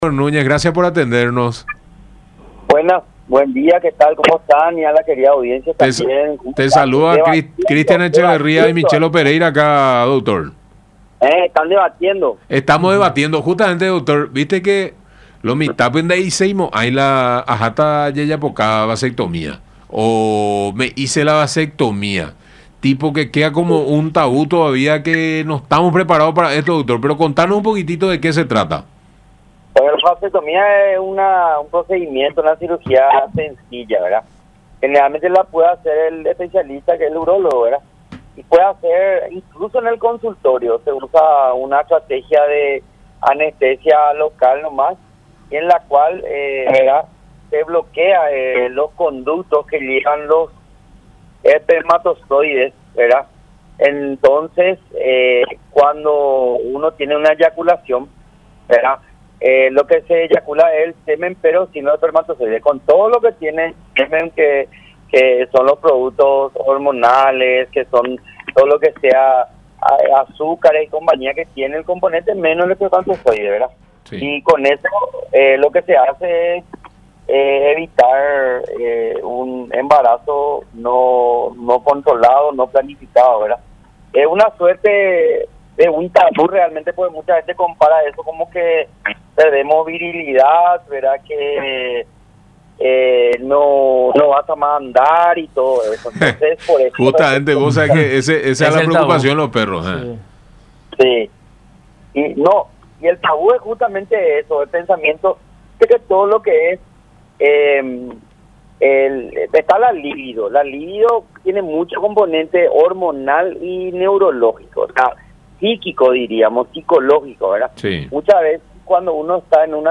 No nos vamos a volver más femeninos, finos ni impotentes con la vasectomía”, agregó en el programa “La Gran Mañana De Unión” por radio La Unión y Unión Tv.